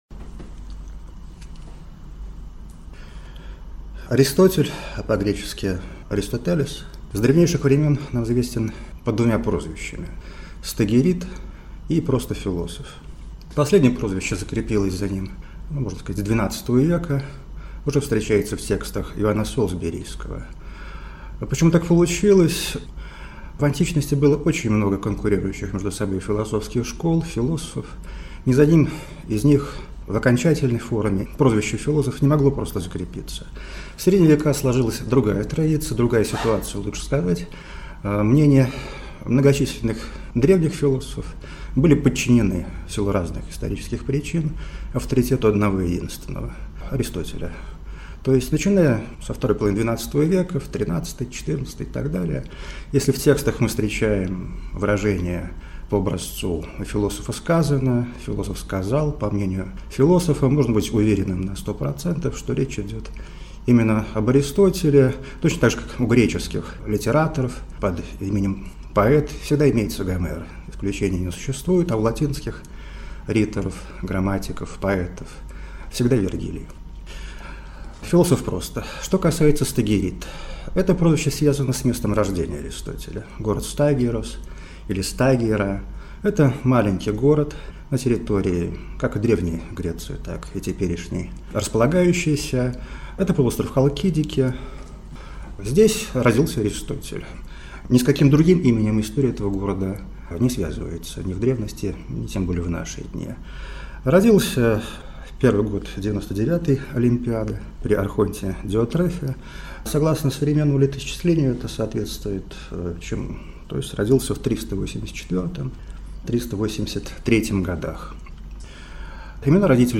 Аудиокнига Жизнь и труды Аристотеля | Библиотека аудиокниг